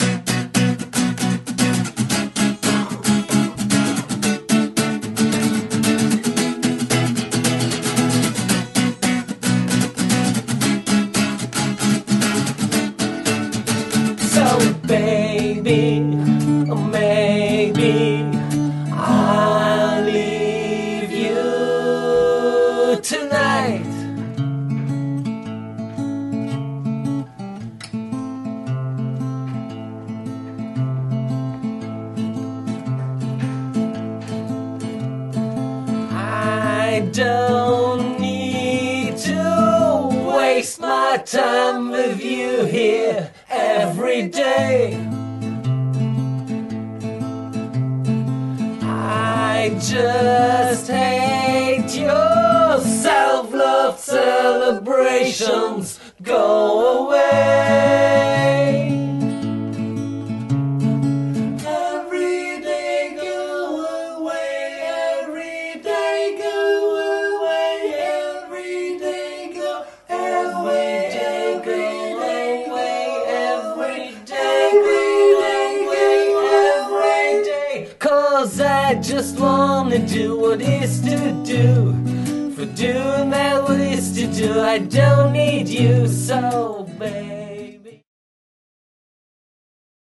ld-voc., ac-g.
voc., ac-g.